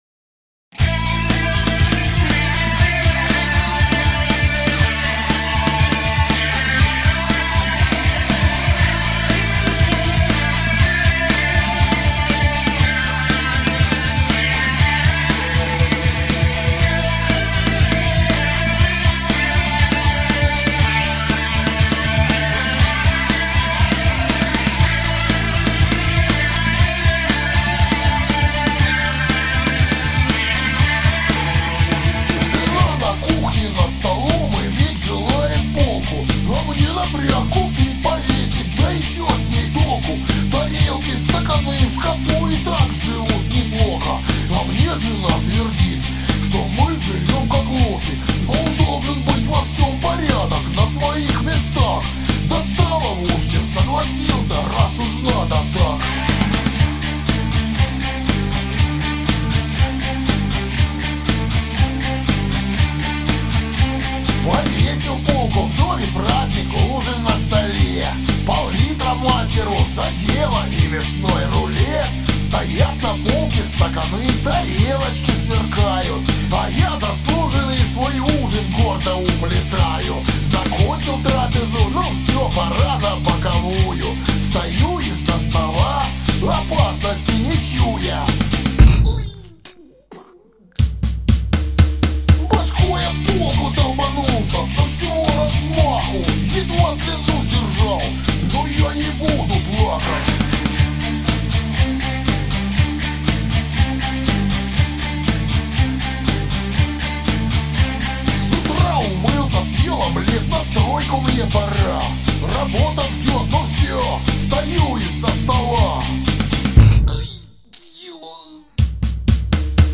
• Жанр: Панк